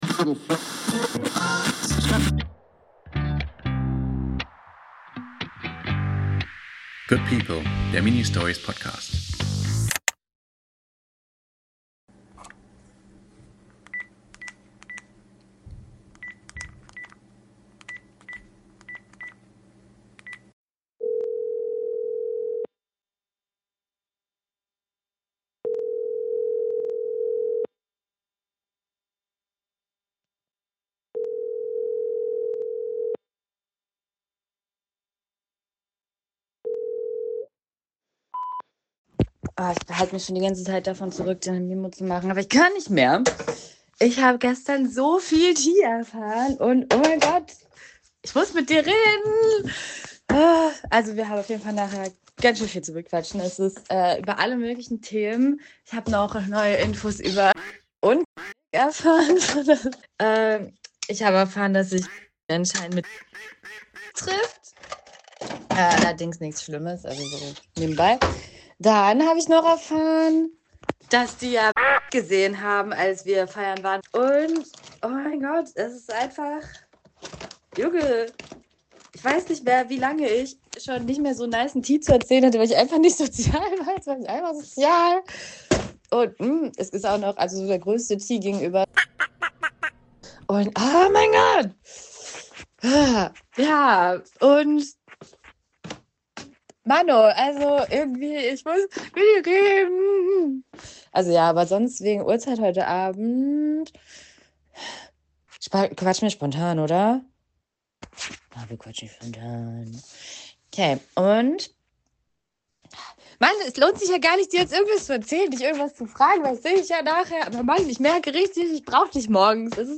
Es tutet. Einmal, zweimal, dreimal – dann der Signalton.
Eine Stimme platzt fast vor Aufregung. Sie sagt, sie muss dir unbedingt etwas erzählen.